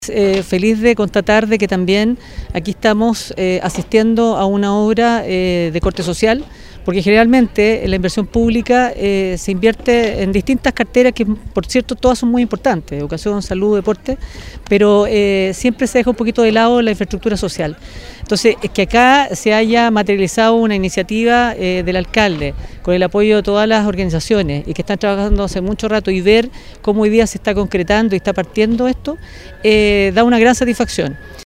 Con una tradicional ceremonia, se le dio el vamos oficial a la construcción del futuro Parque Comunitario de Concón.